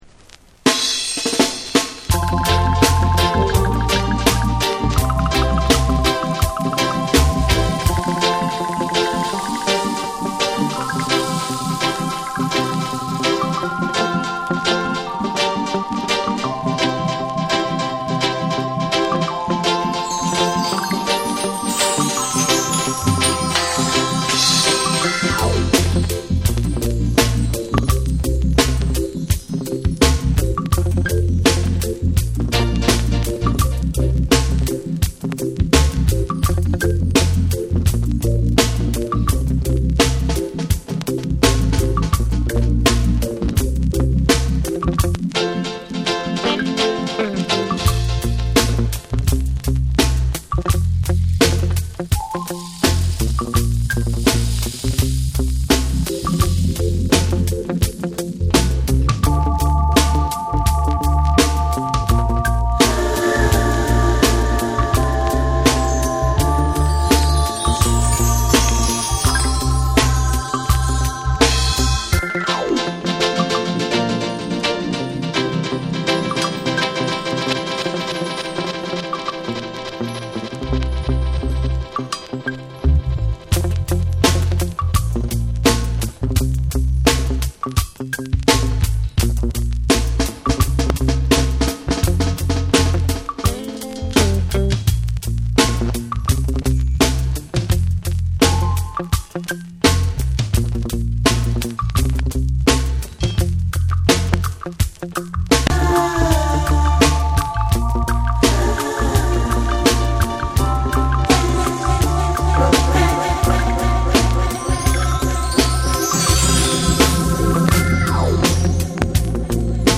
キャッチーでホッコリしたレゲエを聴かせてくれます！
REGGAE & DUB